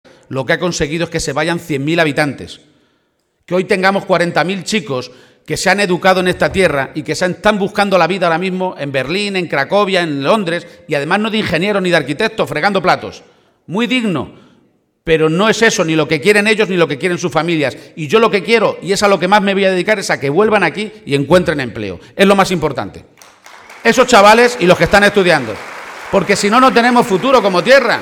Momento del acto público en Mora